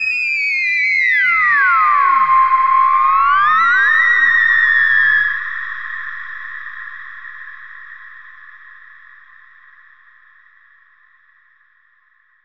Theremin_Swoop_05.wav